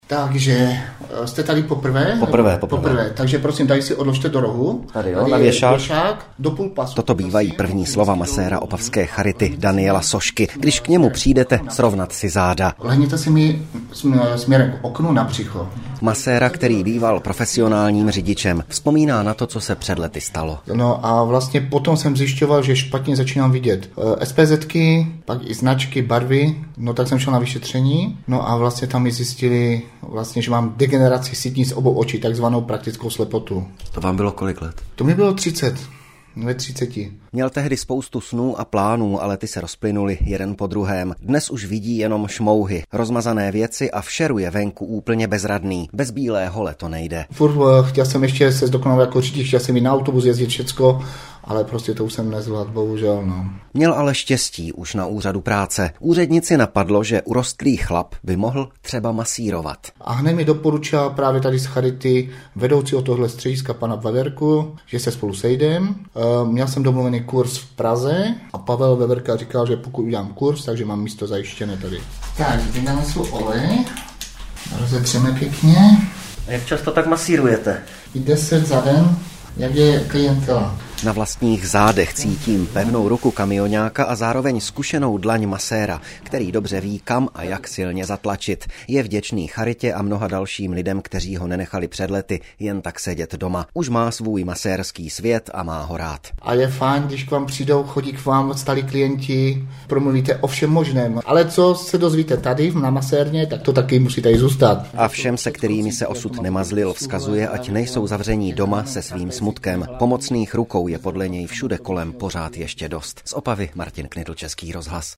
Reportáž nahrávaná na masérském stole
reportaz-nahravana-na-maserskem-stole-2-4.mp3